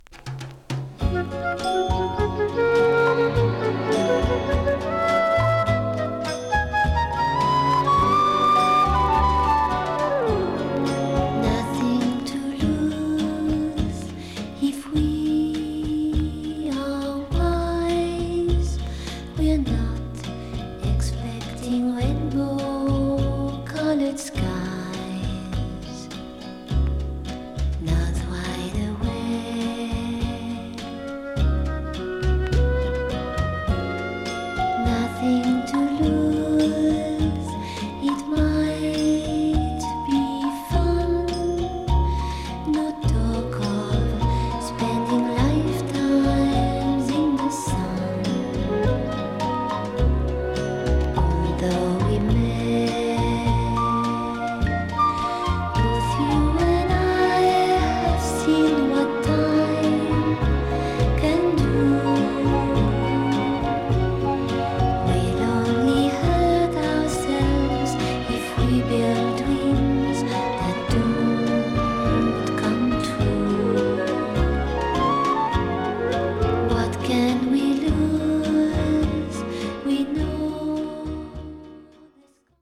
爪弾きギターから極めて簡素なボサノヴァ調のバッキングに可憐に儚く歌う大名曲。